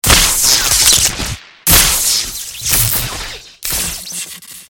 exp_television_sparks1.mp3